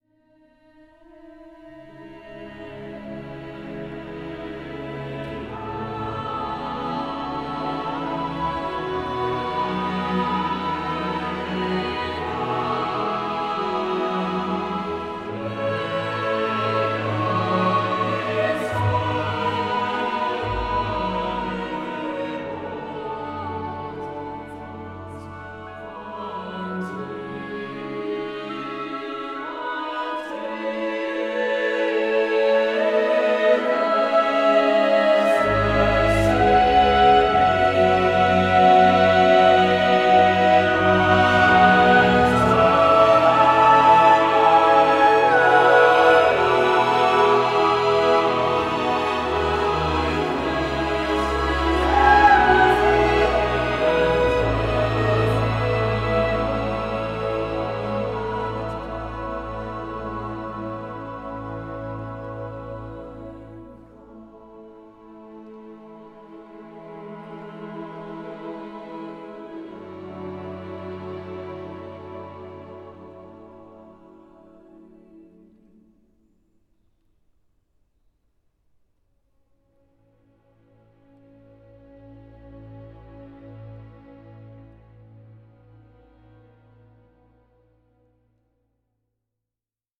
Voicing: Choral Score